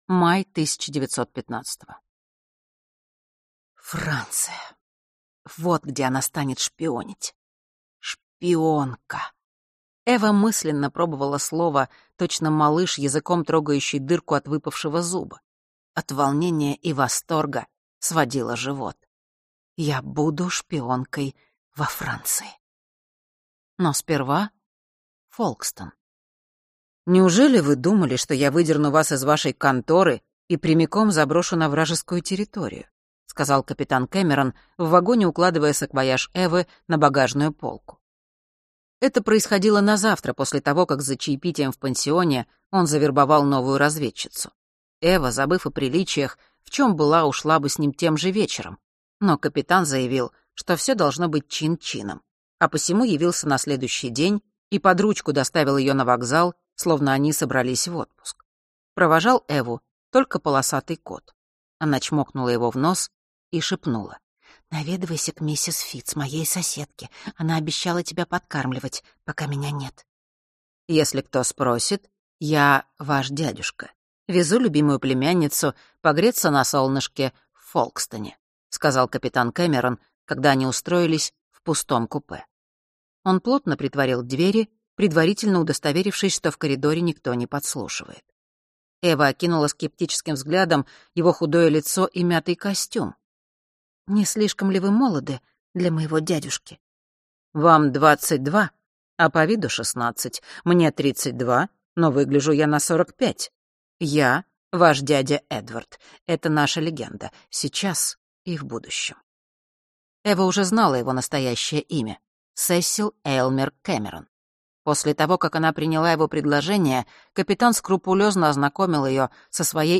Аудиокнига Сеть Алисы | Библиотека аудиокниг